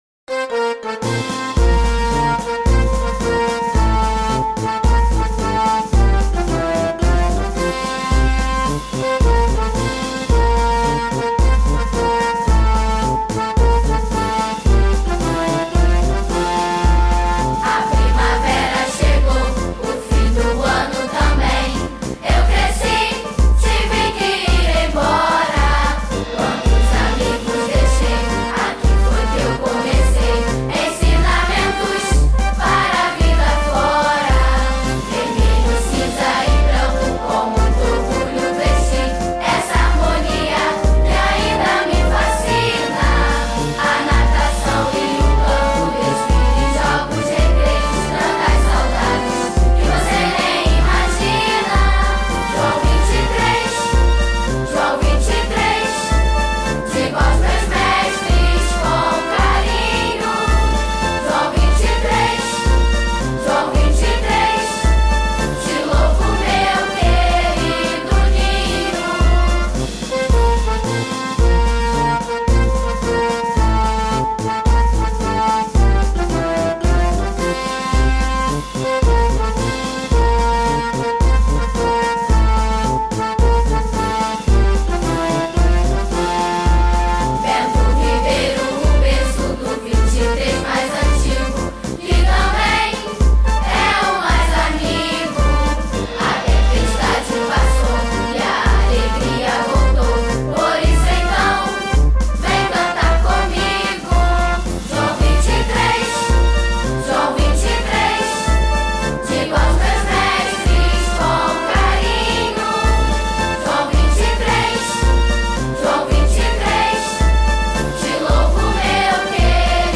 Hino do Colégio